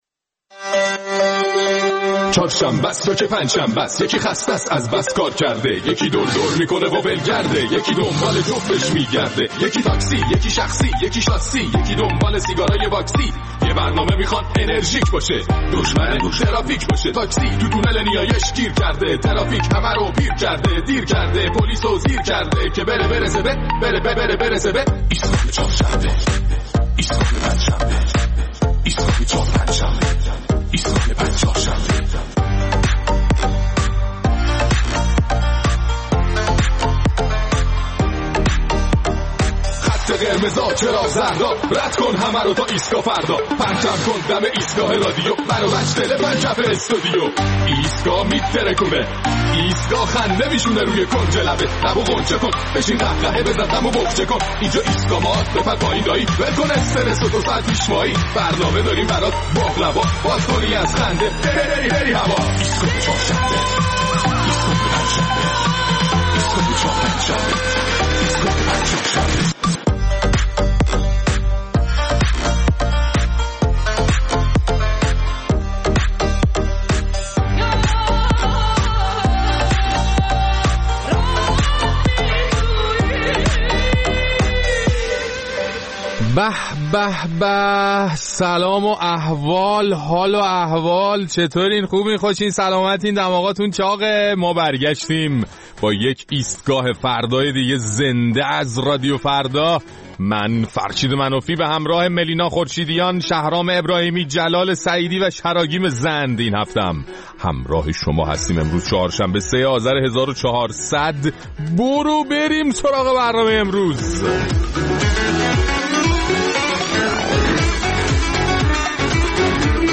در این برنامه نظرات شما را درباره طرح مجلس برای مقابله با حیوانات خانگی می‌شنویم و در ایستگاه فناوری سه متهم اصلی کاهش سرعت اینترنت در ایران از نگاه وزیر ارتباطات را بررسی می‌کنیم.